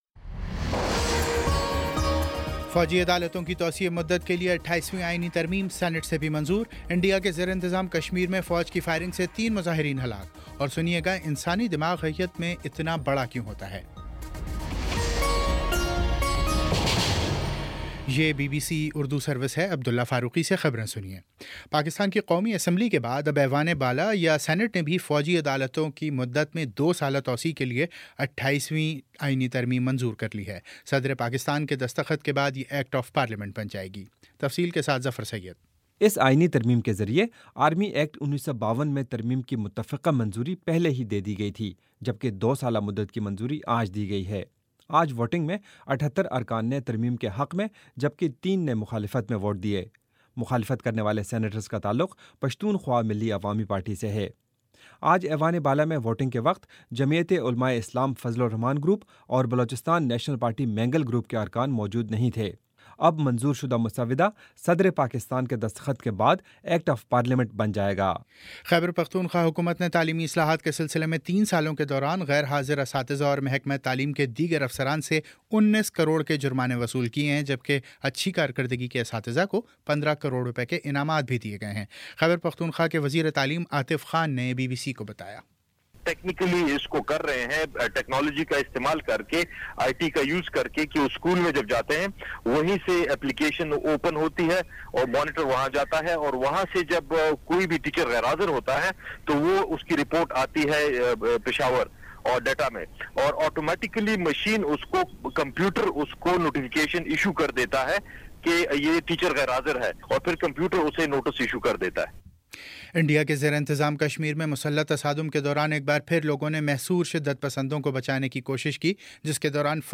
مارچ 28 : شام چھ بجے کا نیوز بُلیٹن